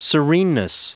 Prononciation du mot sereneness en anglais (fichier audio)
Prononciation du mot : sereneness